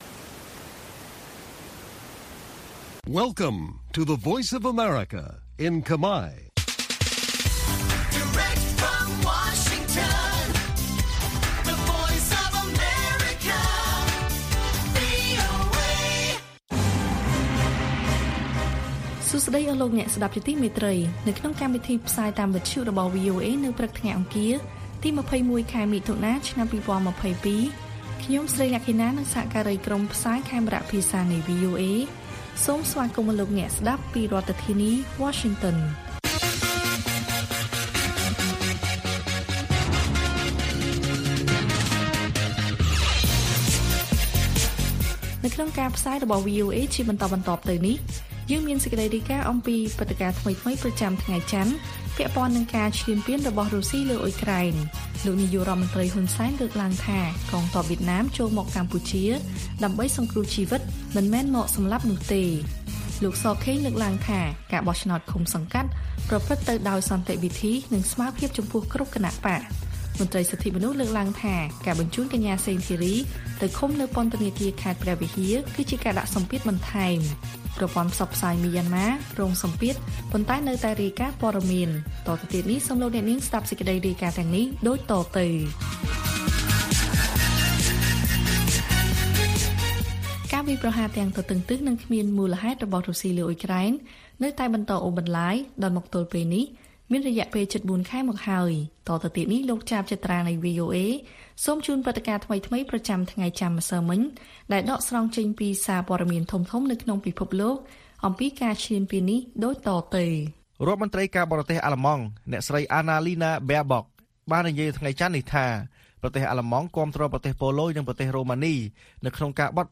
ព័ត៌មានពេលព្រឹក ២១ មិថុនា៖ លោក ហ៊ុន សែន ថាកងទ័ពវៀតណាមចូលមកកម្ពុជាដើម្បីសង្គ្រោះជីវិត មិនមែនមកសម្លាប់នោះទេ